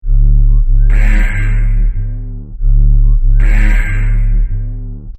reactor.mp3.svn-base